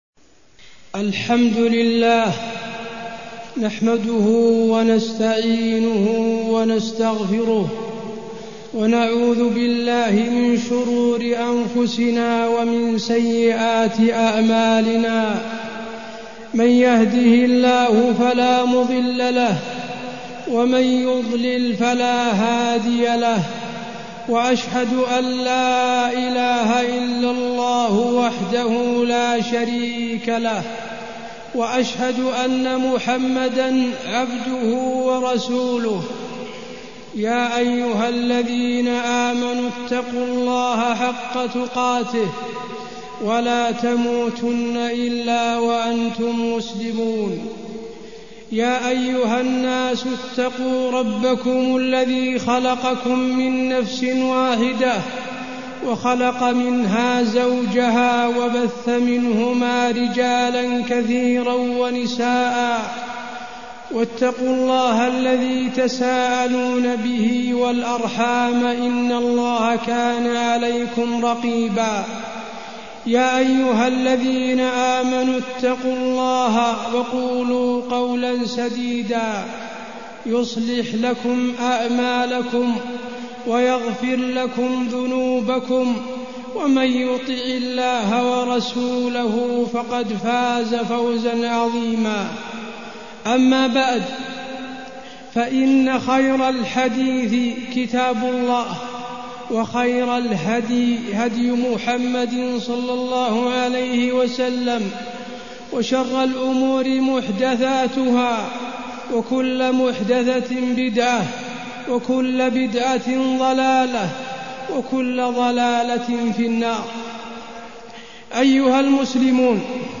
تاريخ النشر ٧ شوال ١٤٢٠ هـ المكان: المسجد النبوي الشيخ: فضيلة الشيخ د. حسين بن عبدالعزيز آل الشيخ فضيلة الشيخ د. حسين بن عبدالعزيز آل الشيخ محاسبة النفس